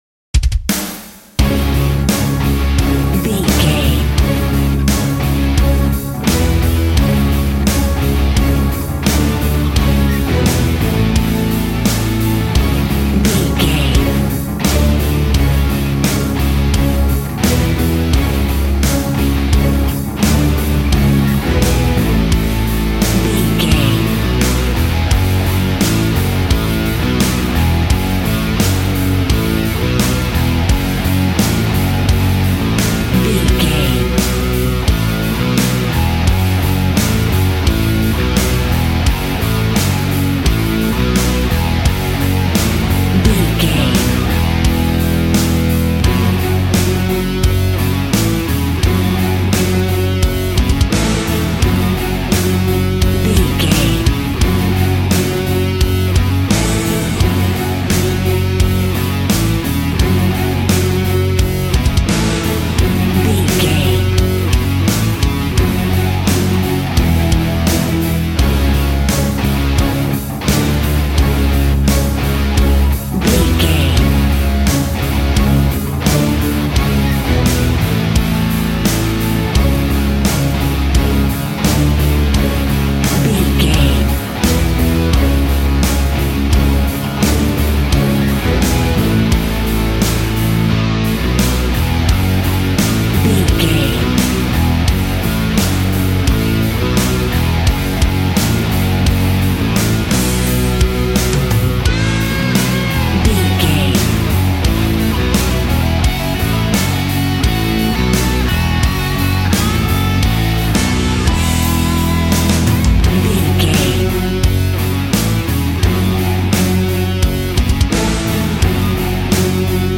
Ionian/Major
angry
aggressive
electric guitar
drums
bass guitar